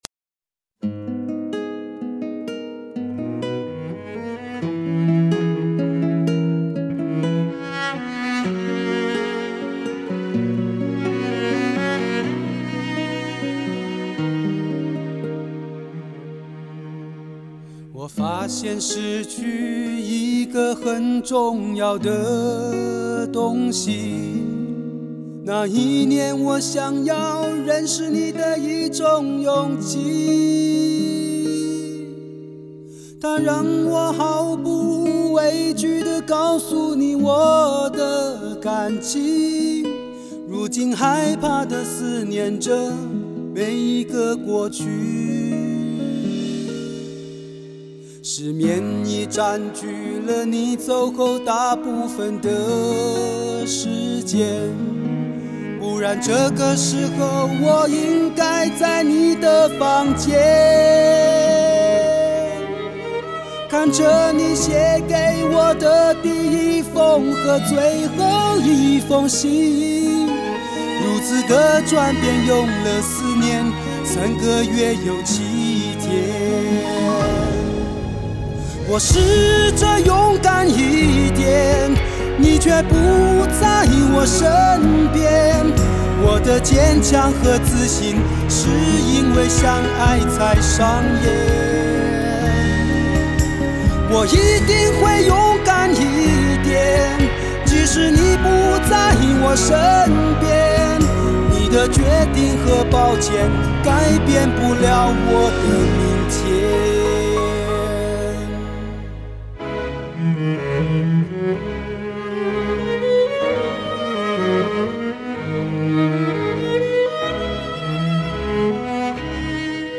流行 收藏 下载